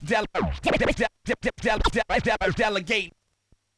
FX
SCRATCHING  (4).wav